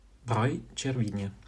Breuil-Cervinia (pron., AFI: fr. [bʁœj] - it. /ʧerˈvinja/[3]